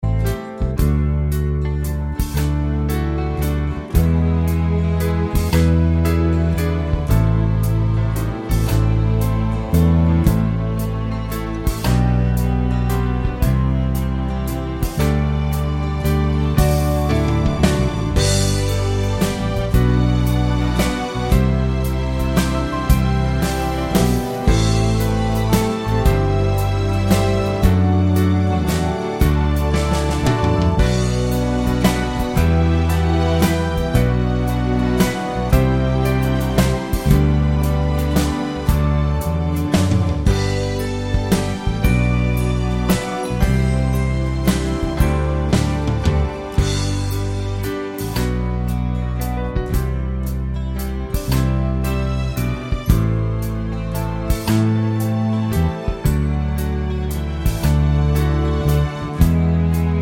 no Backing Vocals Crooners 3:22 Buy £1.50